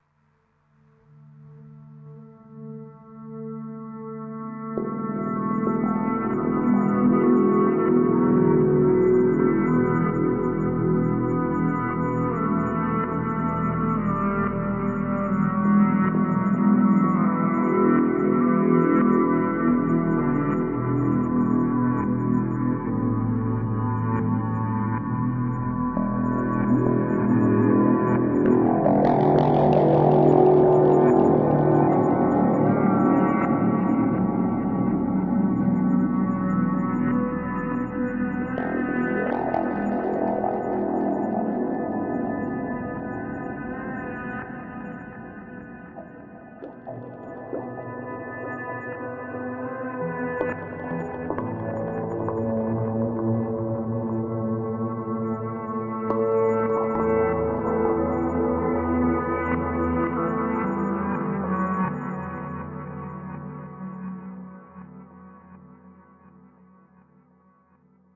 然而更多的合成声音 " abs o5 80吉他
描述：混合的声音，包括我的一些改变很大的吉他。
Tag: 吉他 合成器 气氛 电子 音乐 处理